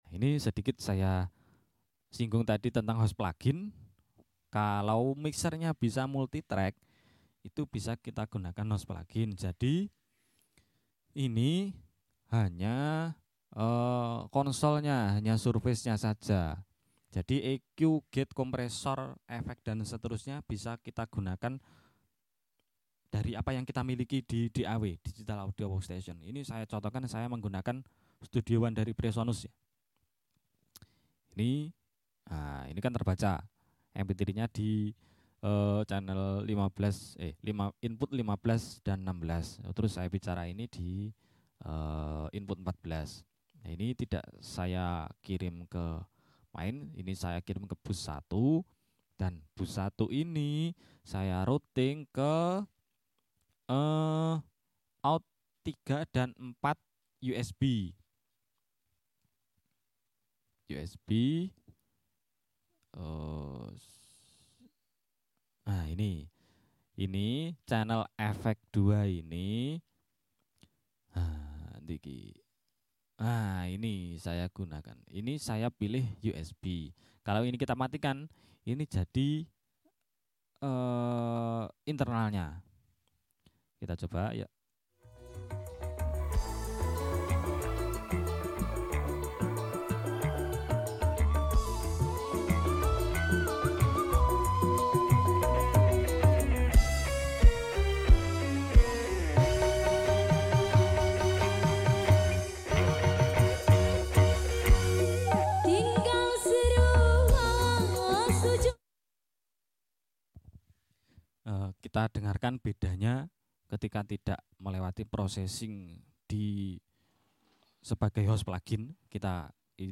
Ini part 2nya, revew mikser sound effects free download